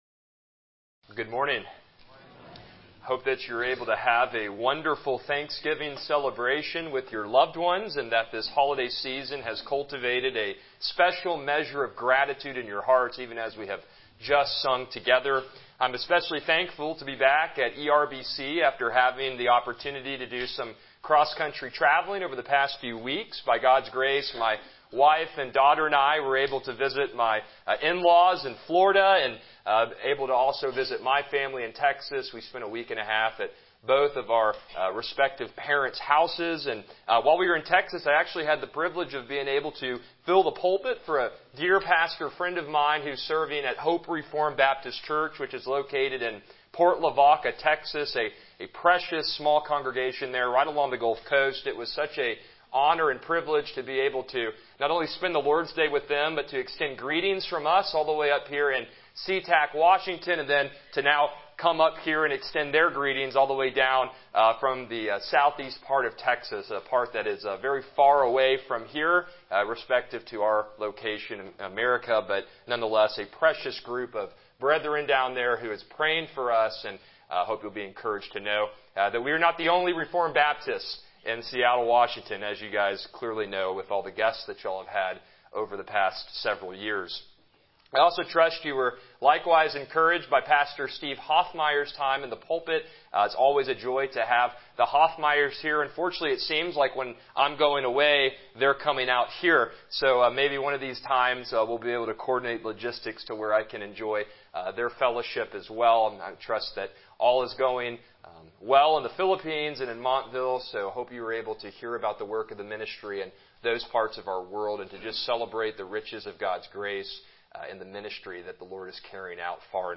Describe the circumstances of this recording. Passage: Psalm 103 Service Type: Morning Worship